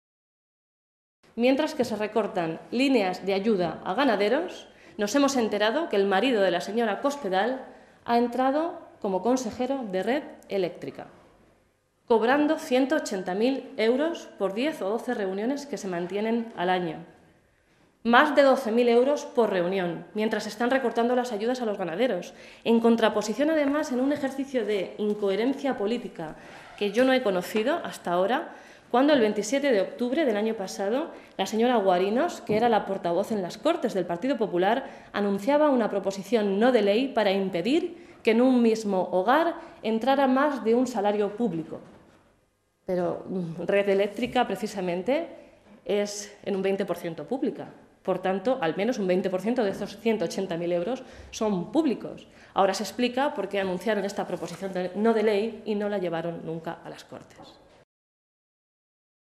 Blanca Fernández, portavoz de Agricultura del Grupo Parlamentario Socialista
Cortes de audio de la rueda de prensa